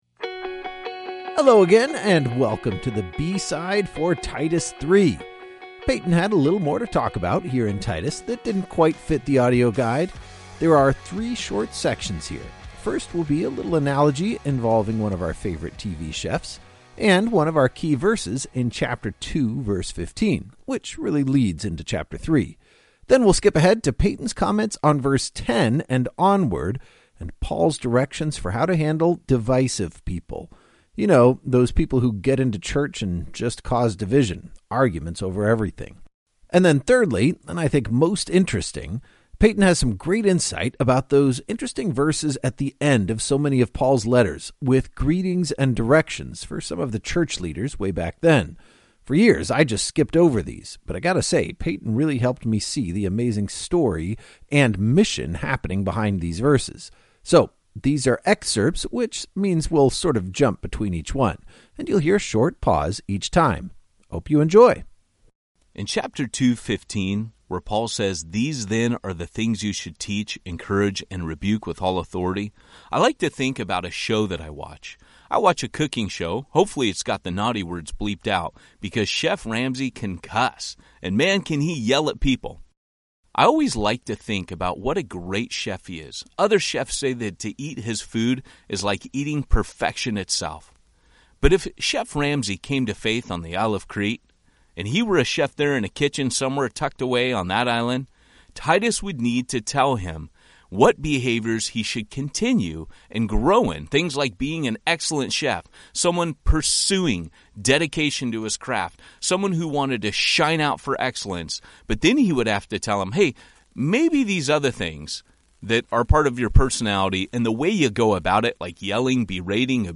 Join Through the Word for an epic journey through all 27 books and 260 chapters of the New Testament. Every chapter brings new insights and understanding as your favorite teachers explain the text and bring the stories to life.